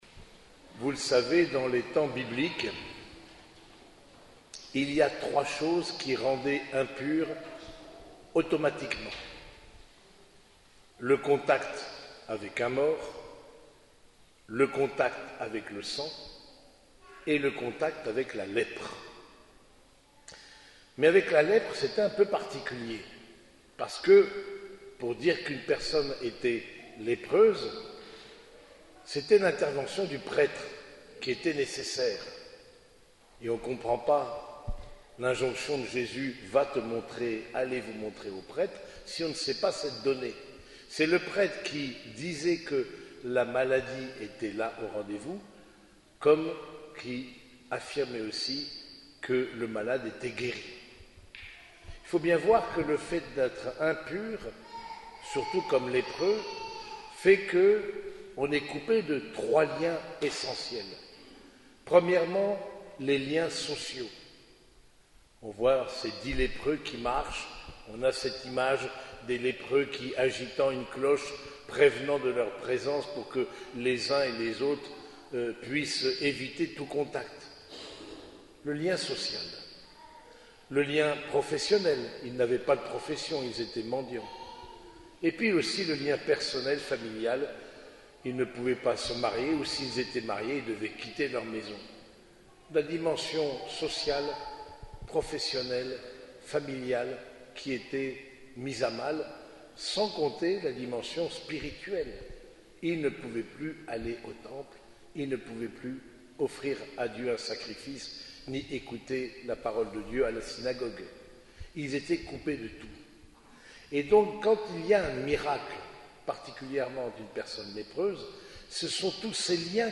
Homélie du troisième dimanche de Pâques